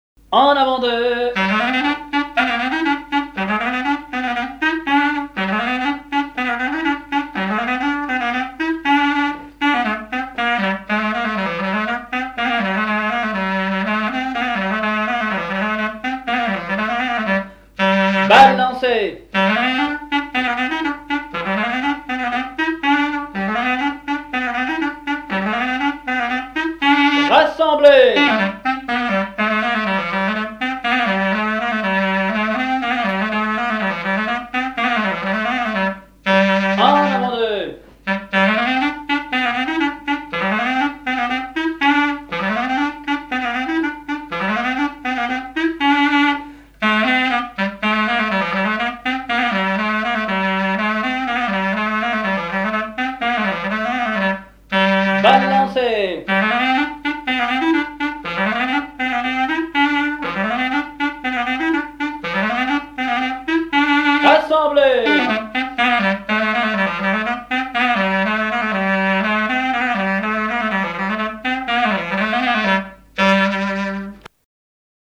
danse : branle : avant-deux
Genre brève
Pièce musicale inédite